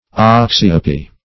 Search Result for " oxyopy" : The Collaborative International Dictionary of English v.0.48: Oxyopia \Ox`y*o"pi*a\, Oxyopy \Ox"y*o`py\, n. [NL. oxyopia, from Gr.